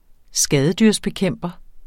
Udtale [ ˈsgæːðədyʁs- ]